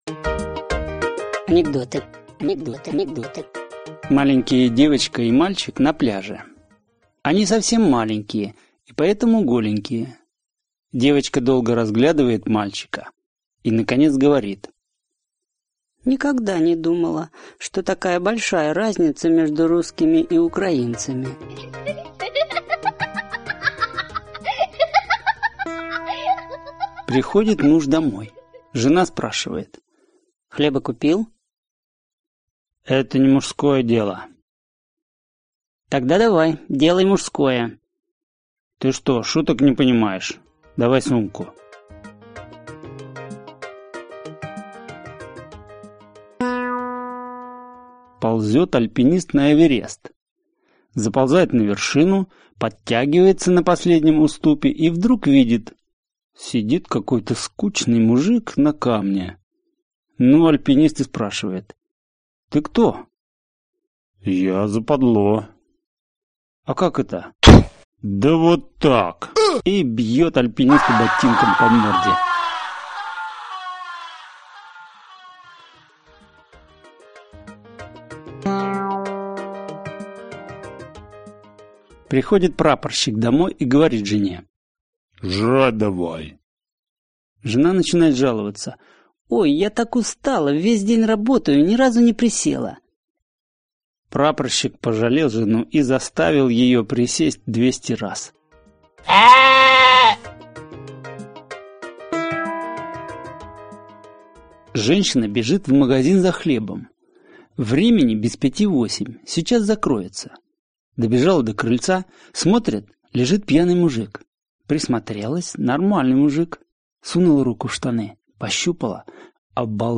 Аудиокнига Анекдоты. выпуск 1 | Библиотека аудиокниг